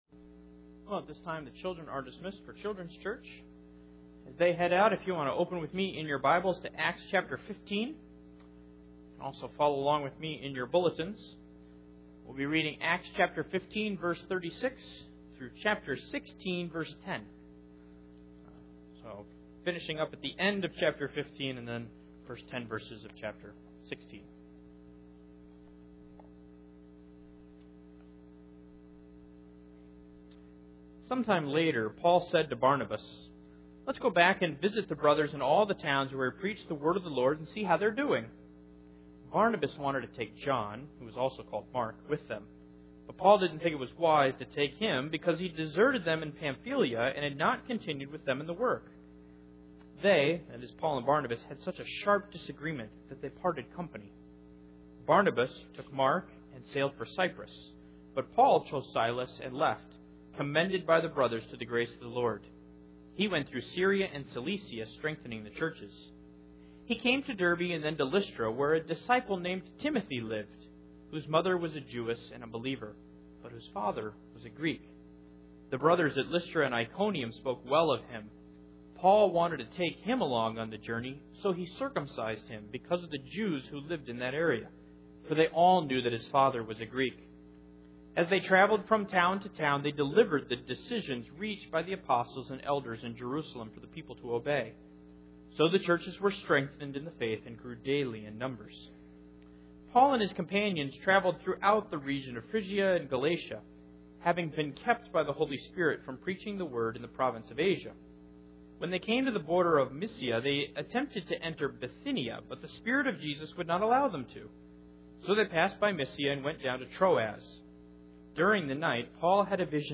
Acts 15:36-16:10 Service Type: Sunday Morning Paul is faced with three decisions.